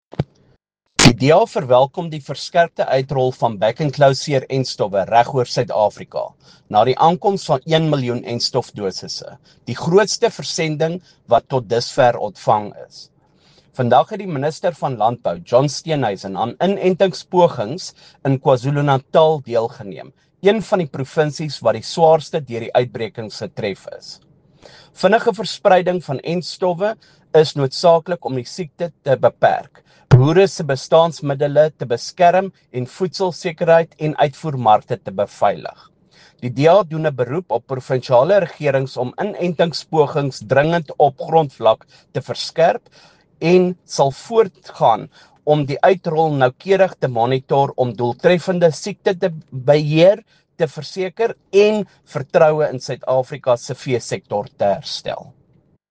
Afrikaans by Beyers Smit MP, DA Member on the Agriculture Portfolio Committee.